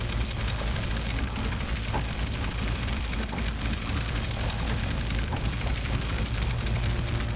pgs/Assets/Audio/Sci-Fi Sounds/Mechanical/Engine 3 Loop.wav at master
Engine 3 Loop.wav